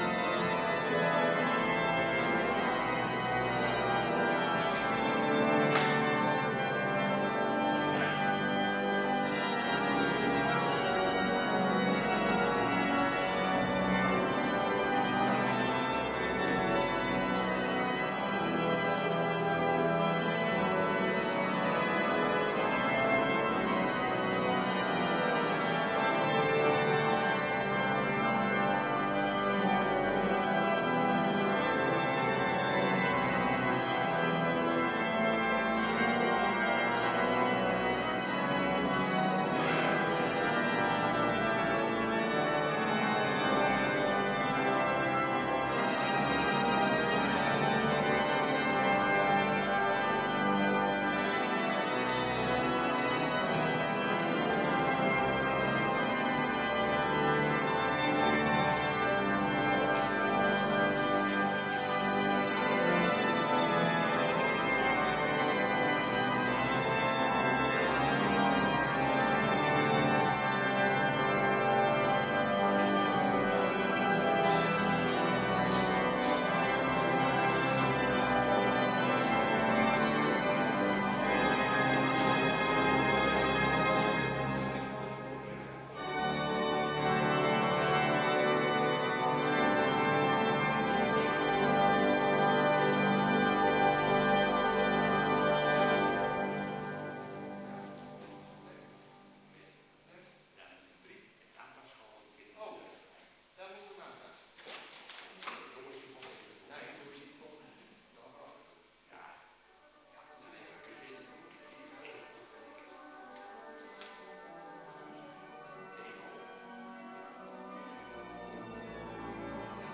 Kerkdiensten